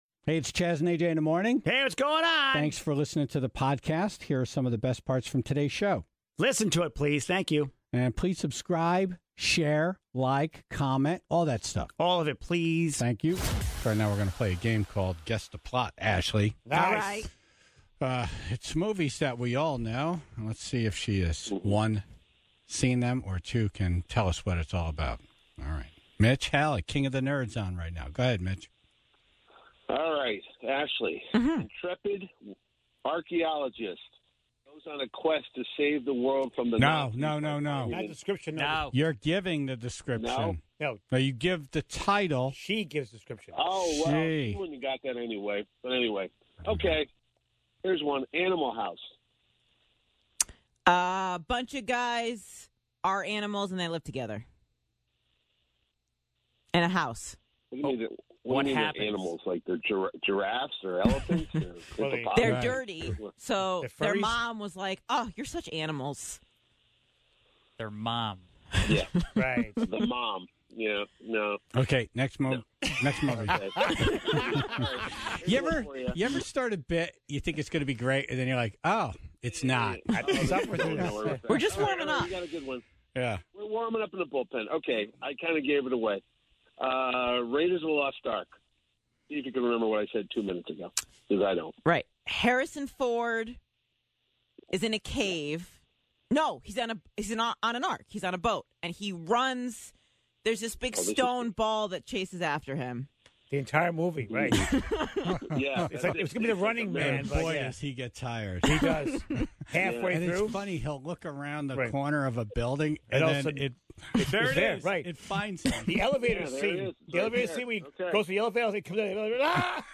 (38:27) During Fun Fact Friday, Bernie Sanders called in to talk about the crazy viral meme of him in a chair wearing mittens, and then David Letterman and Paul Schaffer talked about pineapples.